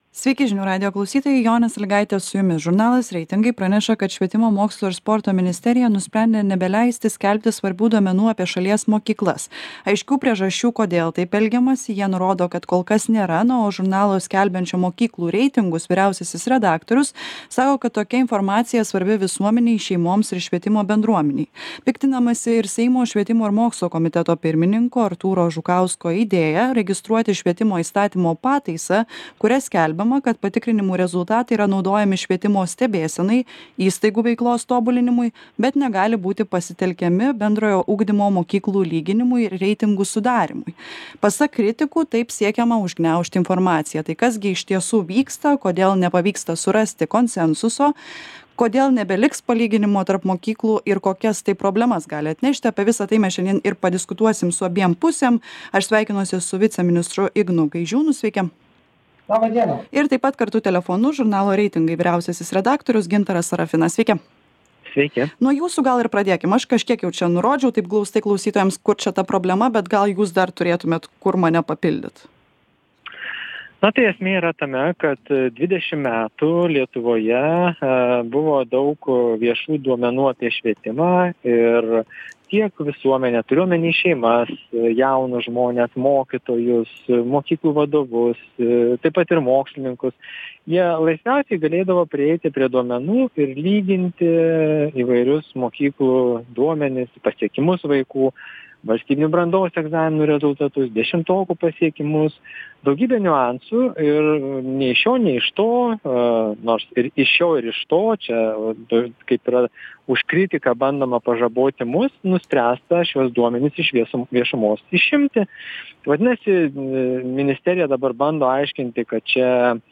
Diskutuojame su švietimo, mokslo ir sporto viceministru Ignu Gaižiūnu